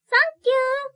ボイス
リアクション女性挨拶